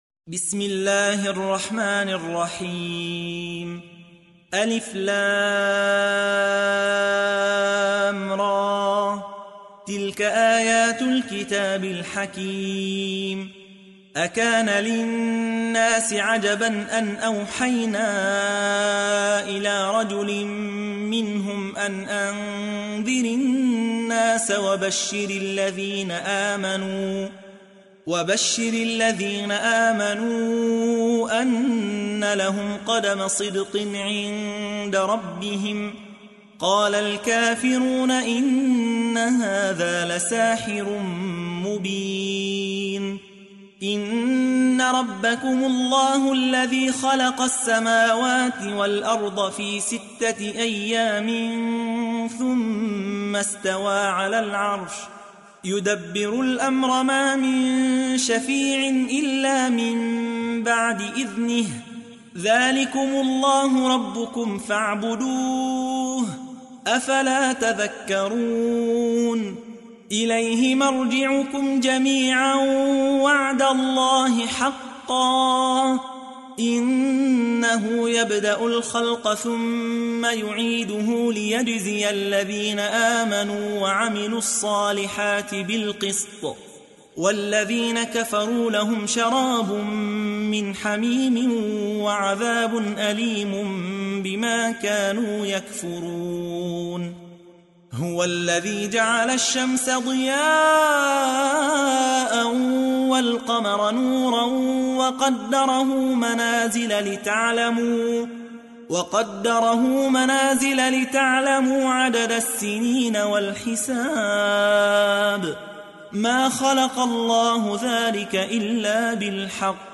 تحميل : 10. سورة يونس / القارئ يحيى حوا / القرآن الكريم / موقع يا حسين